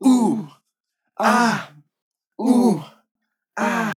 GROUP OHH AHH Sample
Tags: beatbox, Boots And Cats, dry, GROUP, male, OHH AHH, sample, sfx, sounds